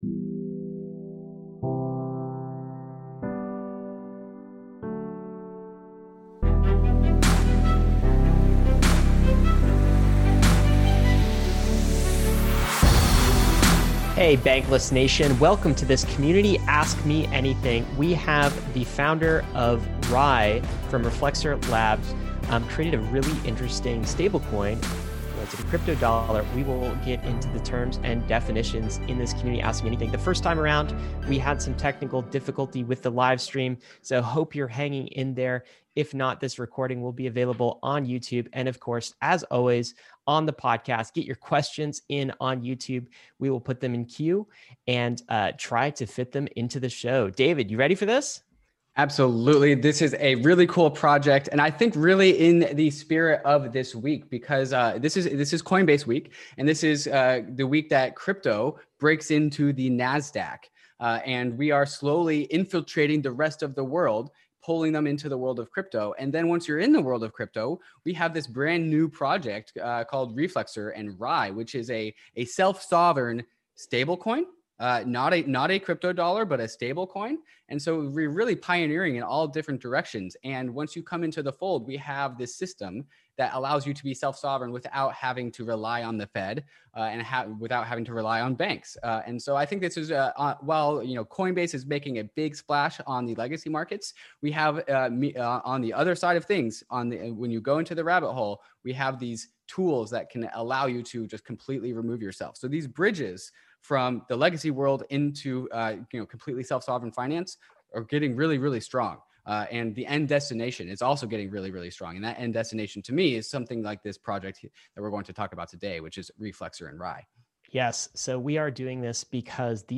Like all our AMAs, the interview is live and incorporates questions from our Inner Circle Discord and live YouTube Chat.